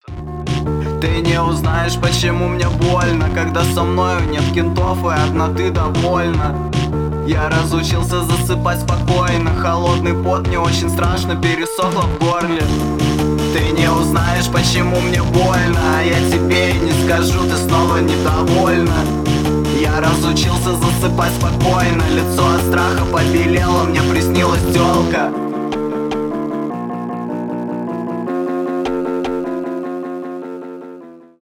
alternative rock
альтернатива